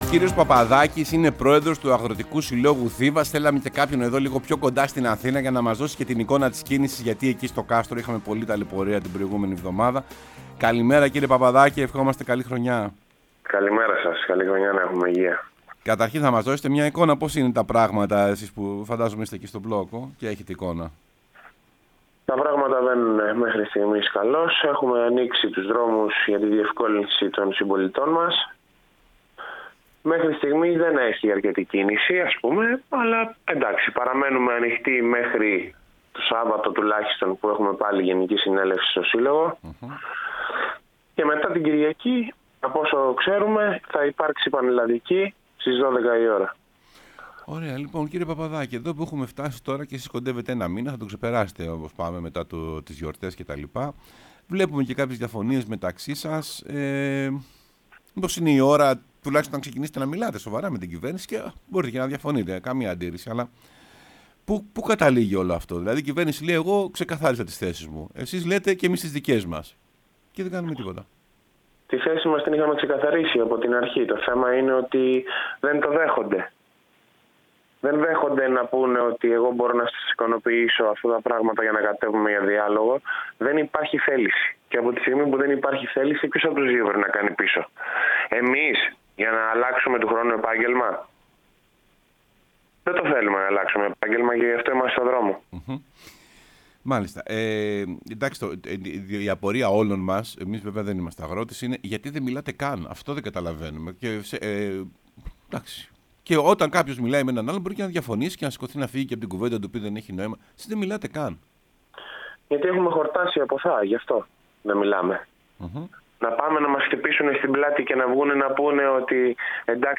ΕΡΤNEWS RADIO Είπαν στο ΕΡΤnews Radio 105.8